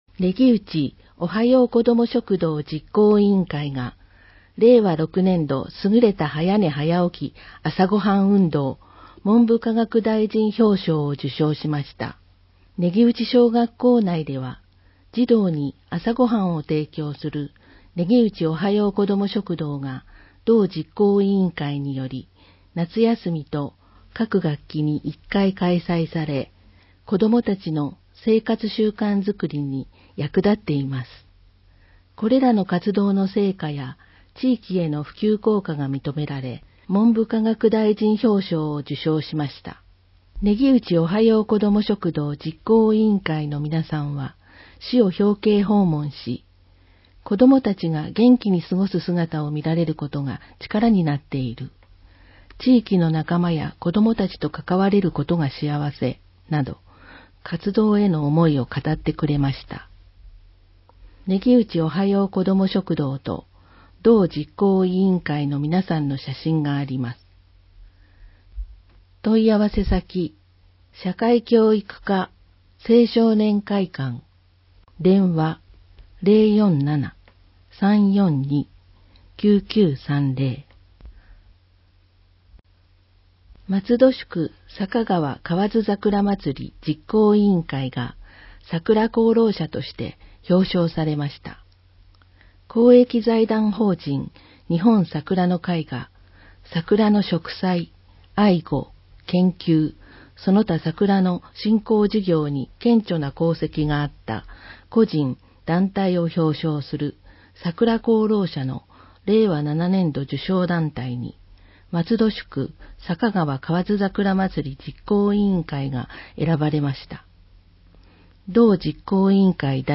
松戸朗読奉仕会のご協力で、広報まつどの音声版を公開しています。